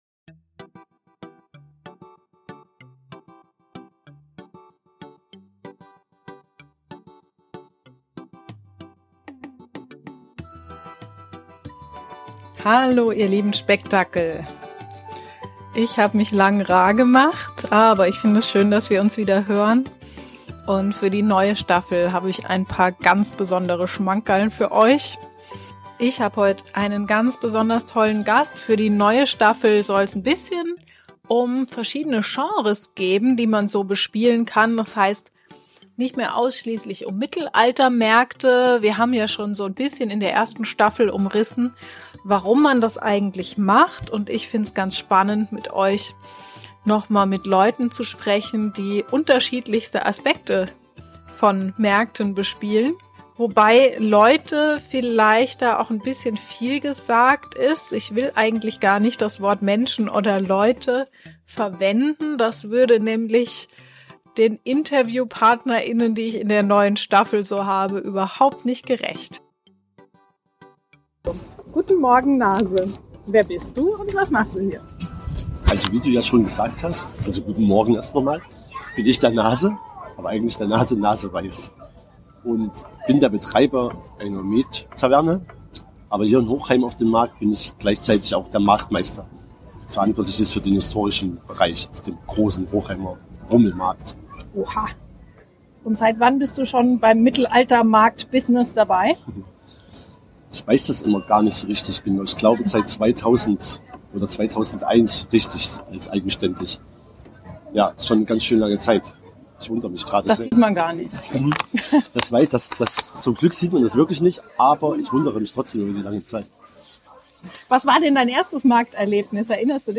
Live aus dem Nähkästchen im Tavernenbackstage